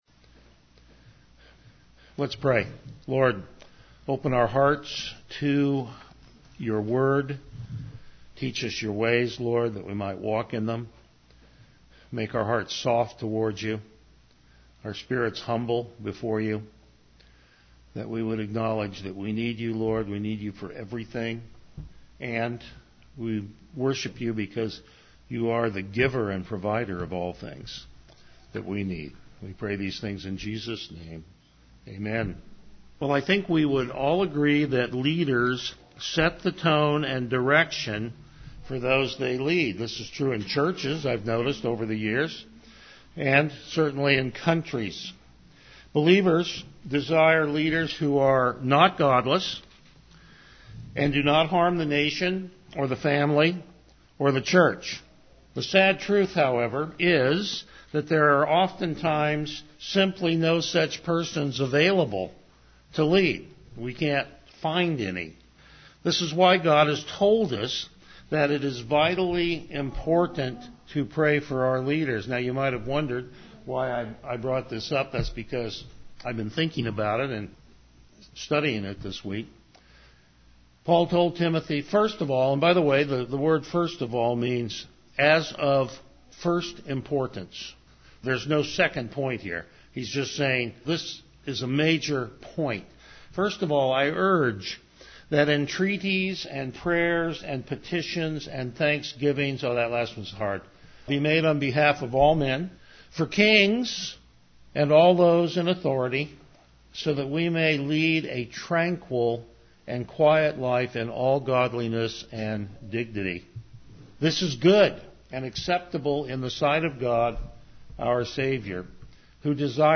Passage: Hebrews 8:1-5 Service Type: Morning Worship
Verse By Verse Exposition